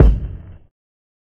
HFMKick7.wav